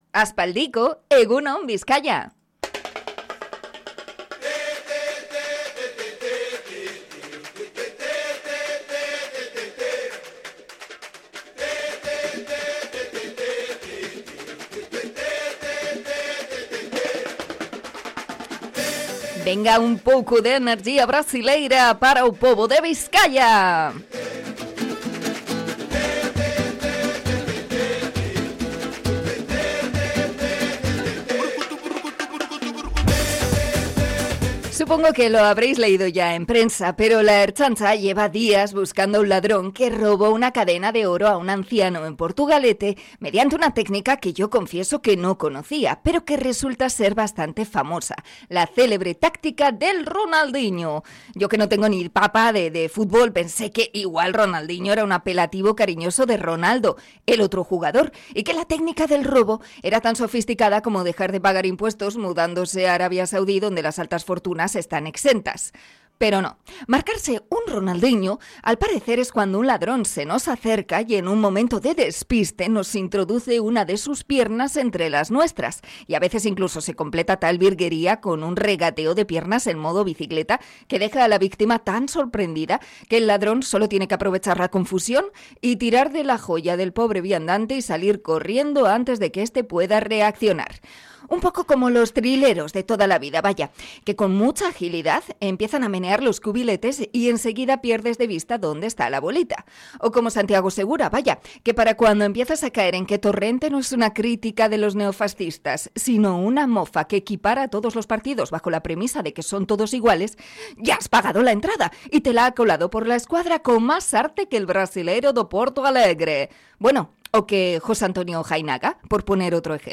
Comentario sobre el absentismo como cortina de humo de Jainaga